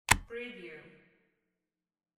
Tape Recorder Close Wav Sound Effect
Description: The sound of closing a microcassette recorder tape door
Properties: 48.000 kHz 16-bit Stereo
Keywords: microcassette, micro, cassette, tape, mini, recorder, door, close, closing
tape-recorder-close-preview-1.mp3